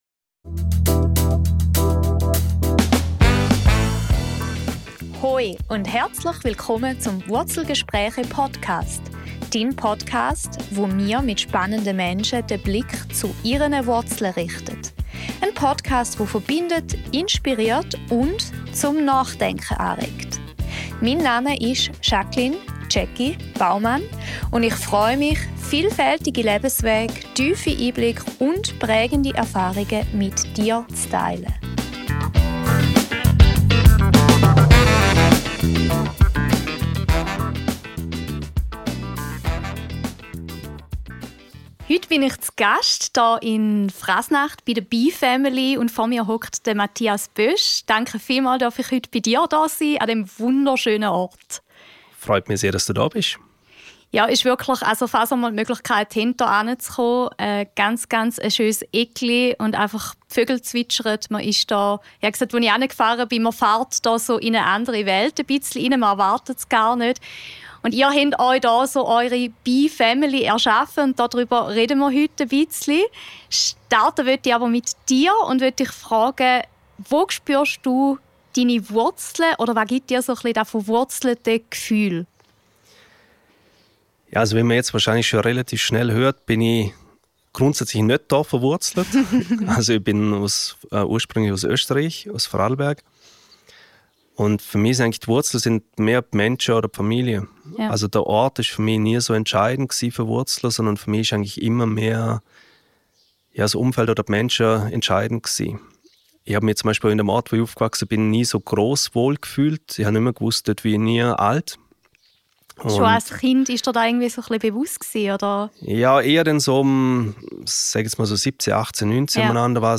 Wir tauchen ein in die Welt der Bienen, sprechen über Unternehmertum im Einklang mit der Natur und darüber, was wir von einem Bienenvolk für unsere eigenen Prozesse lernen können. Ein Gespräch über Verantwortung, Aufklärung und neue Wege im Umgang mit Natur, Wirtschaft und Gemeinschaft.